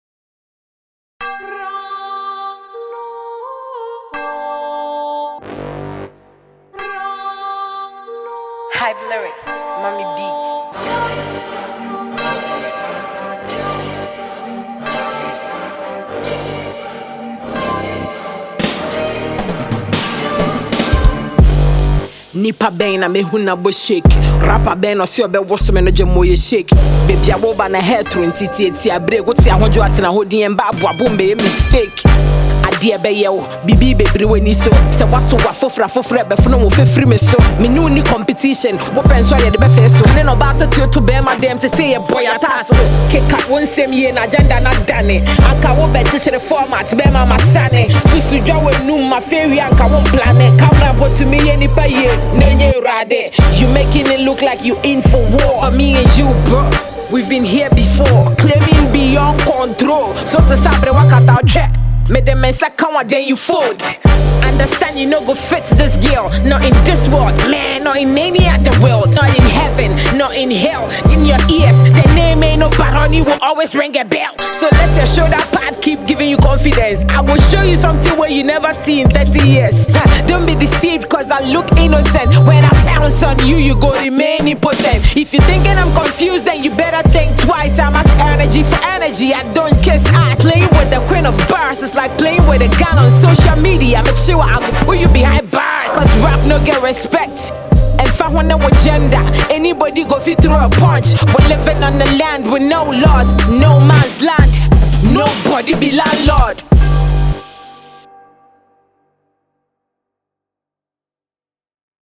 Ghana Music
diss song